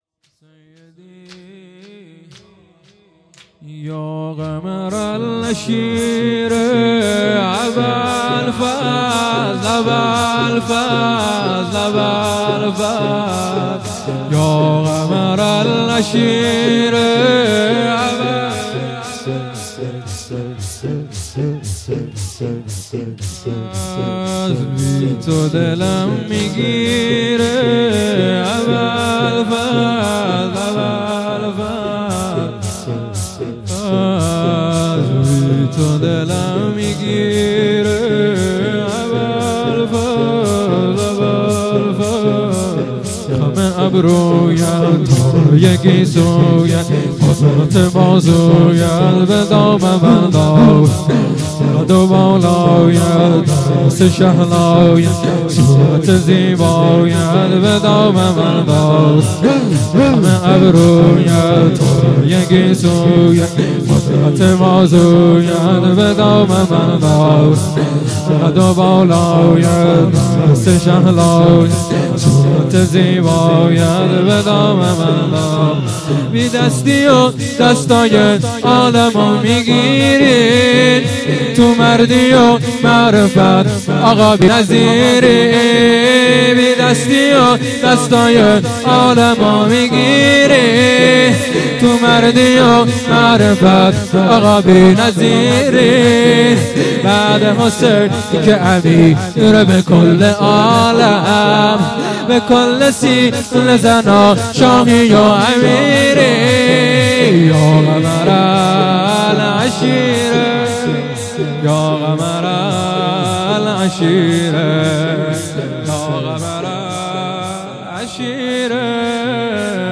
زمینه رجزی
مراسم هفتگی 15 آذر ماه 1399
مداحی